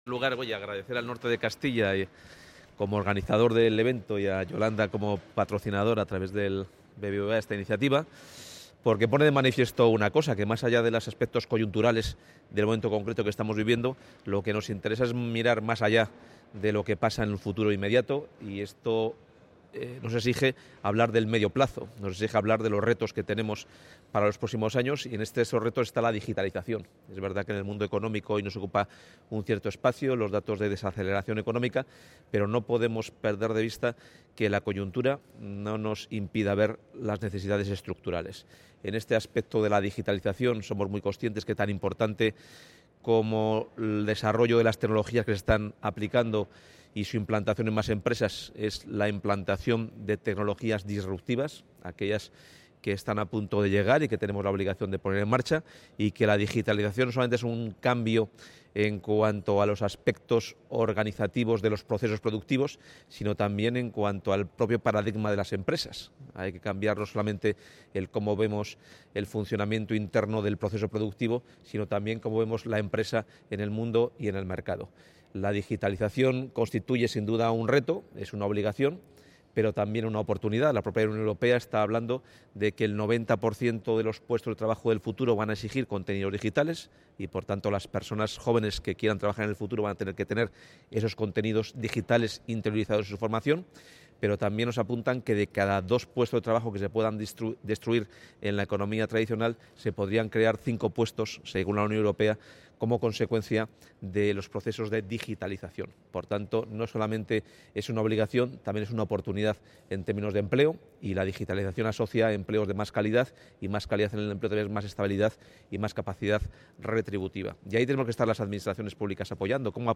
Audio del consejero de Economía y Hacienda en la clausura del desayuno informativo organizado por El Norte de Castilla y BBVA